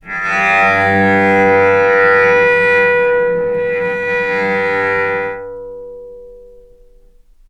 cello / sul-ponticello
vc_sp-G2-mf.AIF